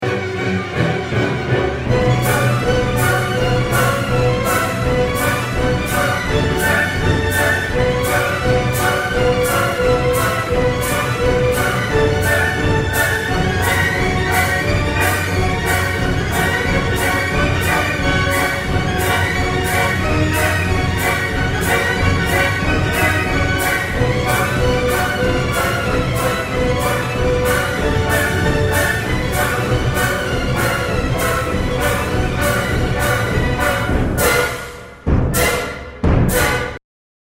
Here it is at full speed:
You can definitely hear the fast marching sound: